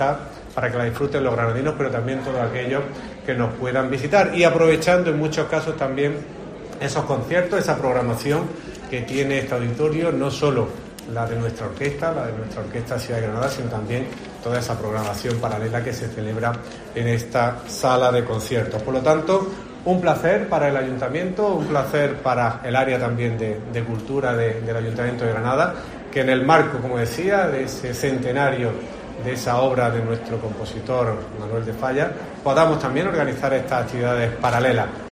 Jorge Saavedra, teniente de alcalde del Ayuntamiento